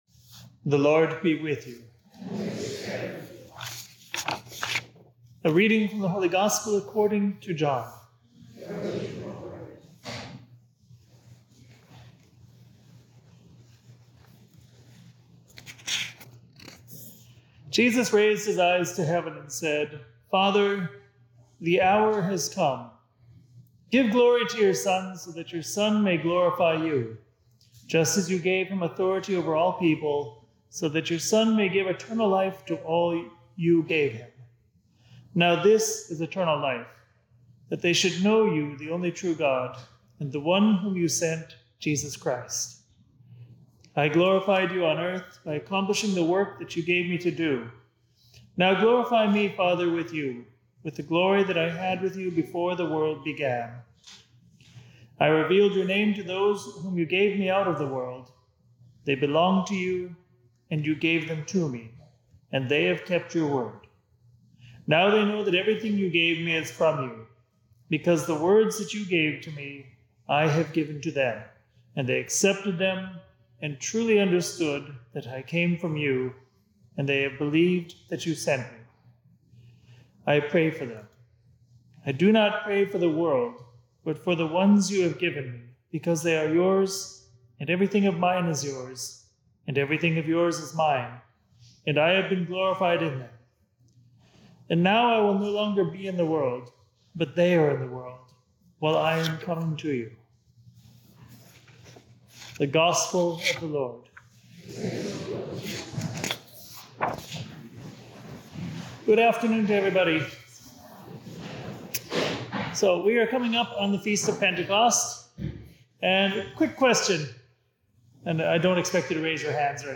Homily
for the 7th Sunday of Easter at St. Patrick Church in Armonk, NY.